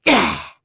Neraxis-Vox_Landing.wav